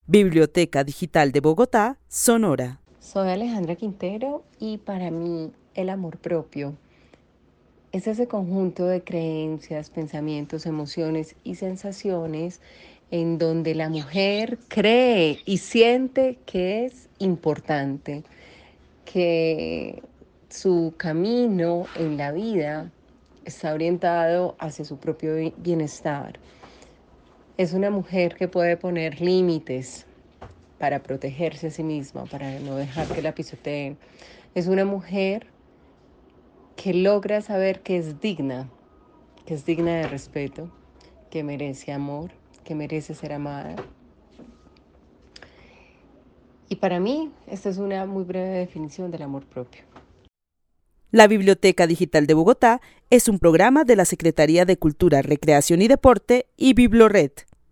Narración oral de una mujer que vive en la ciudad de Bogotá y define el amor propio como un conjunto de creencias, pensamientos y sensaciones que permiten que las mujeres se sientan importantes y puedan poner límites para protegerse. Para ella el amor propio también es sentirse digna de respeto y amor.